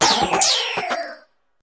Cri de Cryodo dans Pokémon HOME.